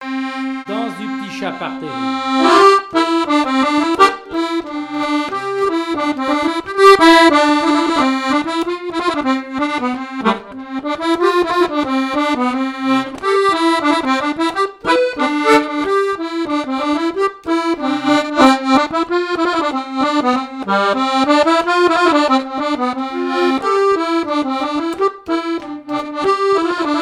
Saint-Georges-de-Montaigu
Chants brefs - A danser
danse : scottich trois pas
répertoire de chansons, et d'airs à danser
Pièce musicale inédite